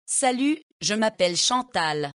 Female
French - Canada